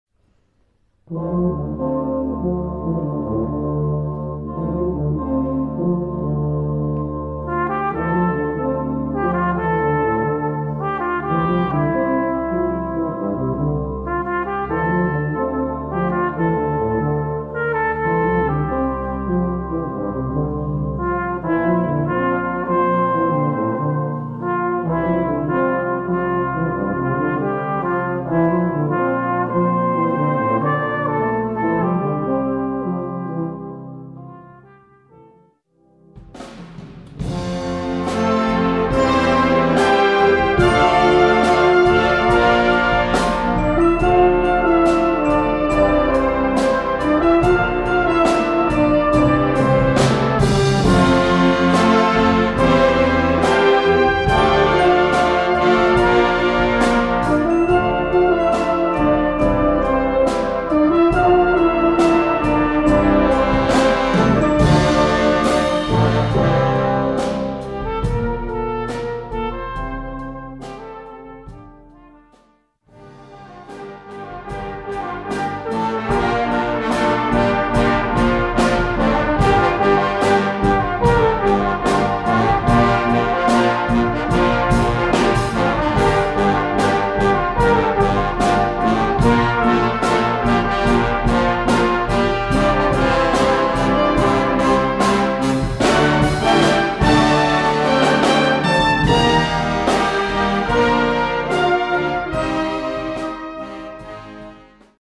Gattung: Moderner Einzeltitel
A4 Besetzung: Blasorchester PDF